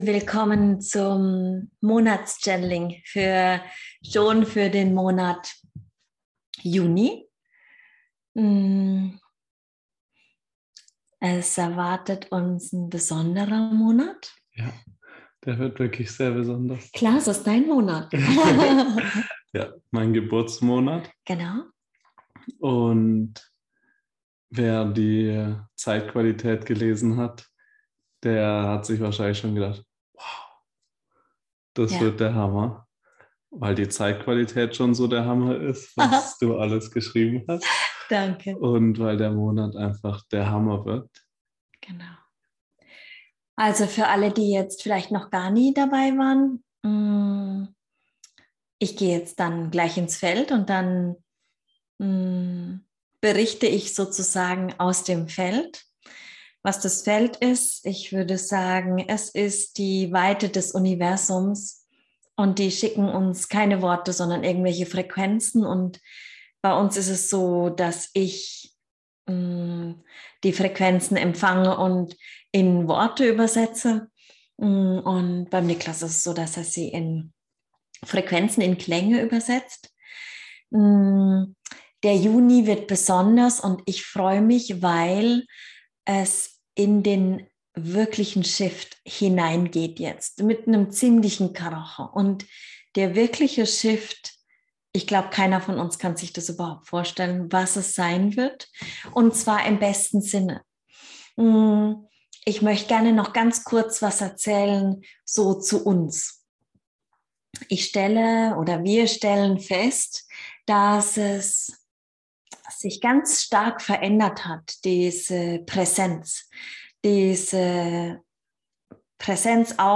Channeling | LIVE Monatsbotschaft Juni 2022 ~ MenschSein - musst du leben. Nicht denken.